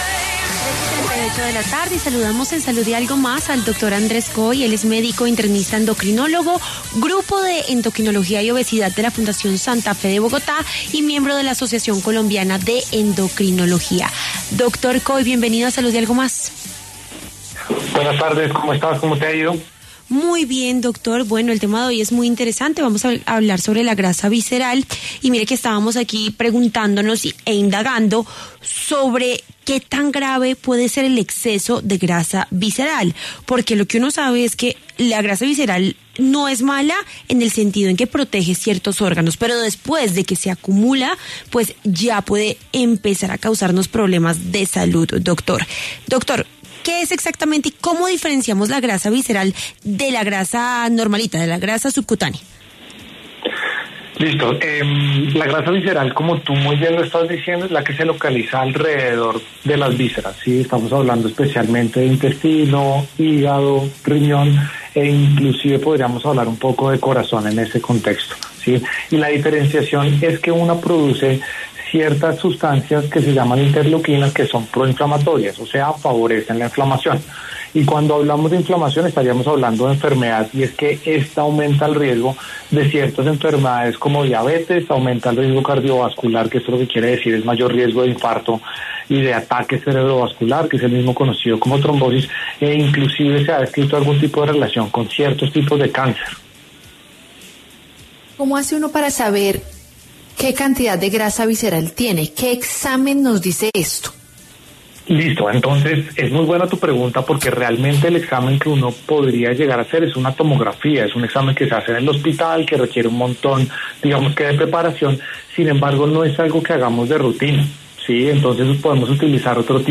endocrinólogo